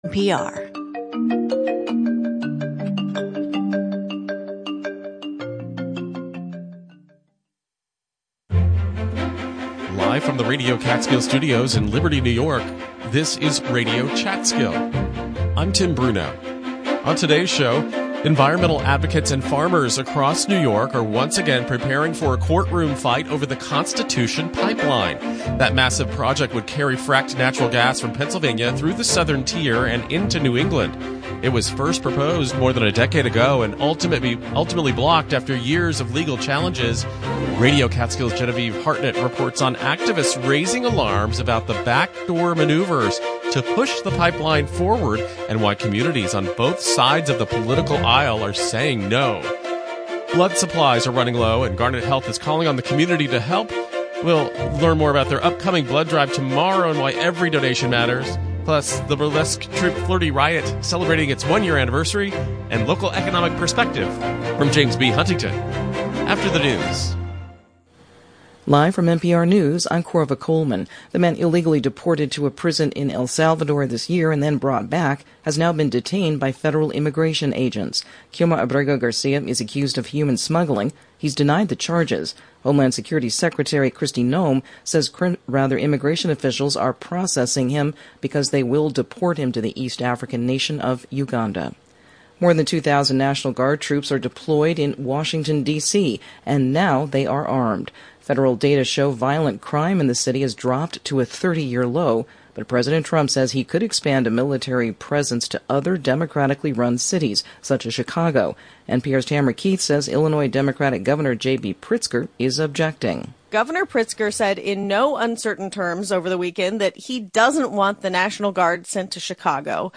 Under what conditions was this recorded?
Live, local conversations focused on arts, history, and current news.